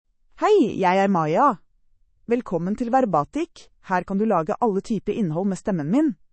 Maya — Female Norwegian Bokmål AI voice
Maya is a female AI voice for Norwegian Bokmål (Norway).
Voice sample
Listen to Maya's female Norwegian Bokmål voice.
Female